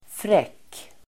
Uttal: [frek:]